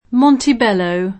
vai all'elenco alfabetico delle voci ingrandisci il carattere 100% rimpicciolisci il carattere stampa invia tramite posta elettronica codividi su Facebook Montebello [ monteb $ llo ] top. e cogn. — anche con pn. ingl. [ montib $ lëu ] come top. in paesi di lingua inglese (Australia, S. U., Can.)